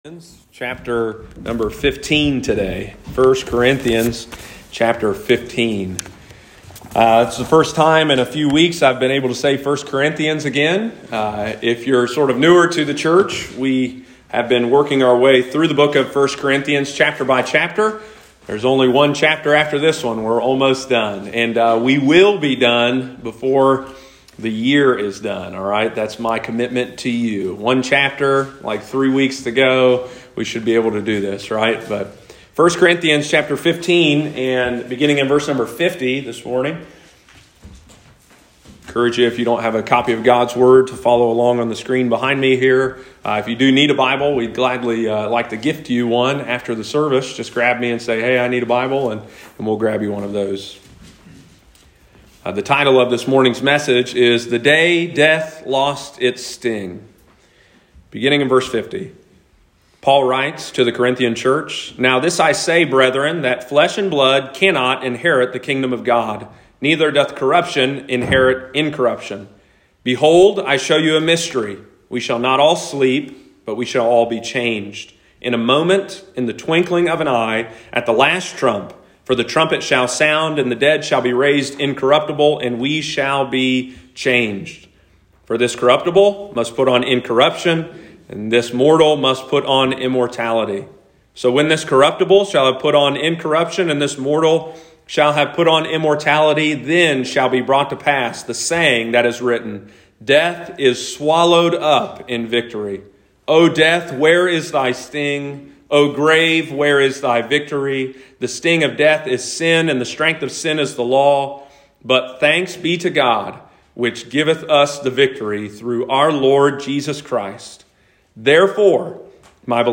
Sunday morning, December 5, 2021.